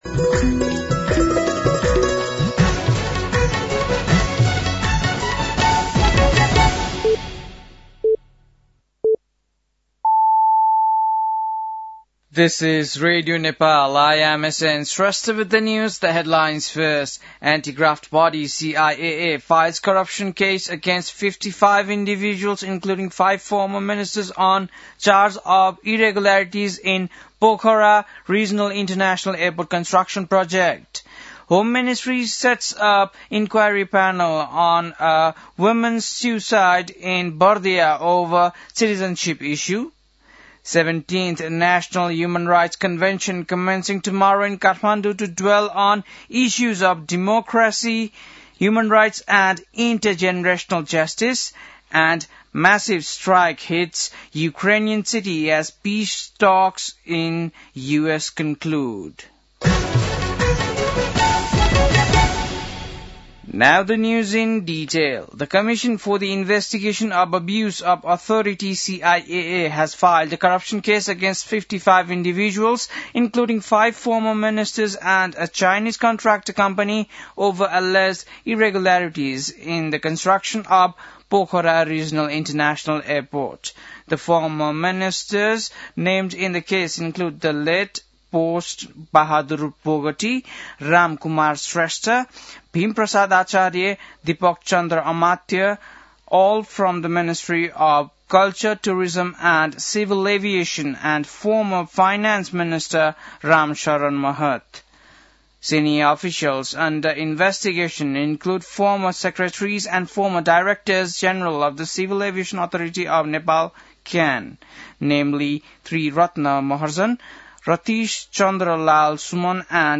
बेलुकी ८ बजेको अङ्ग्रेजी समाचार : २१ मंसिर , २०८२
8.-pm-english-news-.mp3